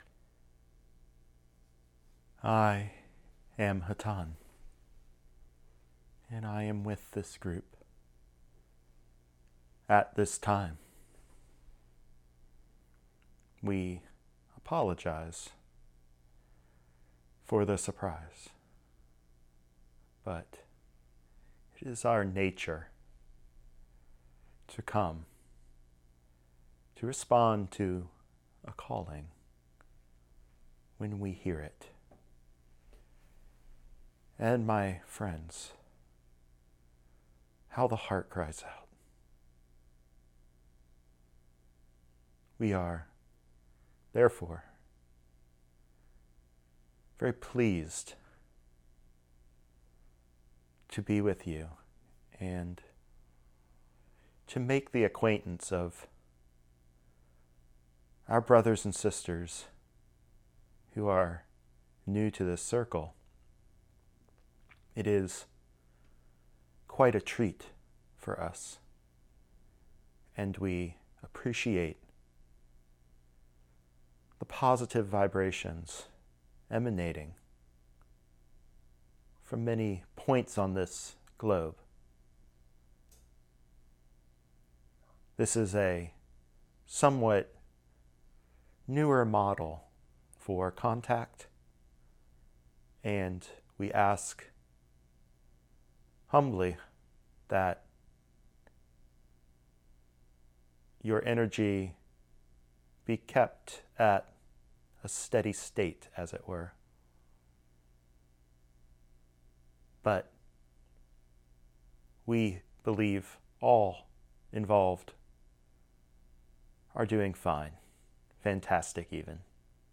This is the reason for the pauses towards the end.